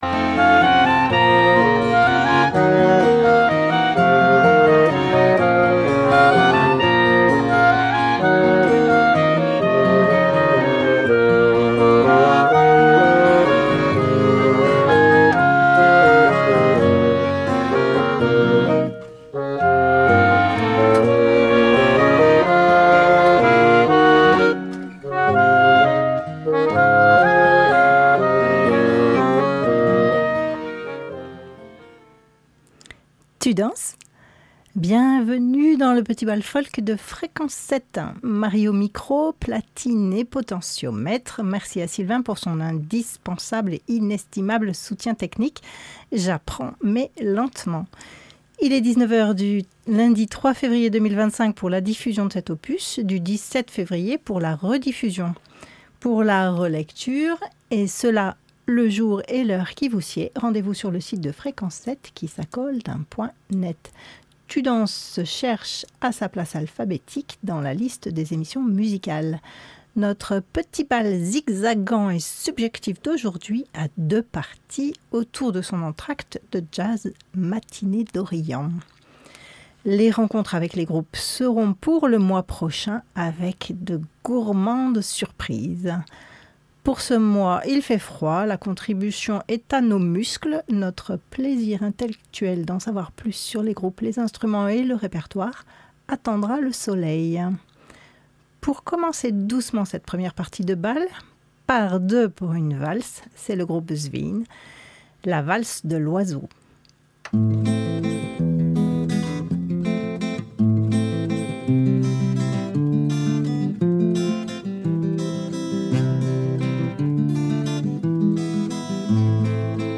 balade irlandaise
bourr�e 2 temps
valse � 22 temps
bourr�e 3 temps